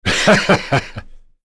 Ricardo-Vox-Laugh.wav